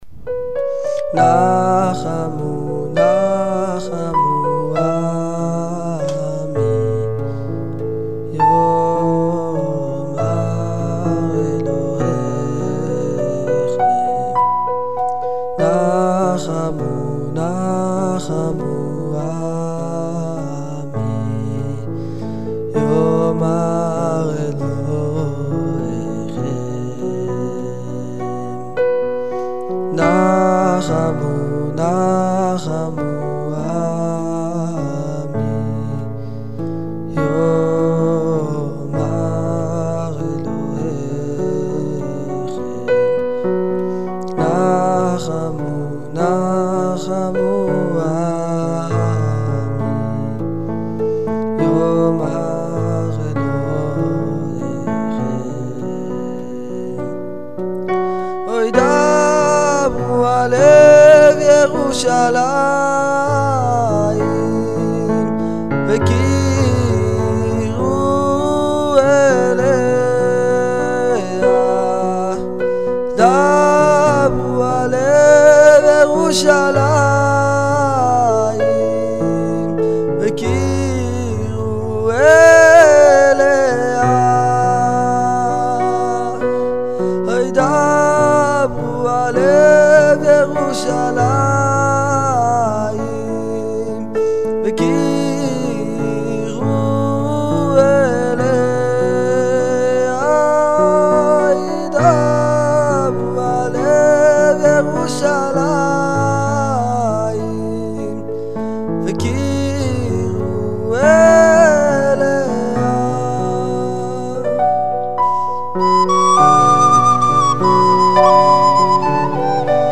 מיד בתחילת האילתור נשמע כאילו זהו- נגמר.
השיר יפה, יש קפיצה בהתחלה של השיר.
יש לך קול נעים לשמיעה, אהבתי.
מי על החלילית?